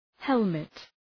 {‘helmıt}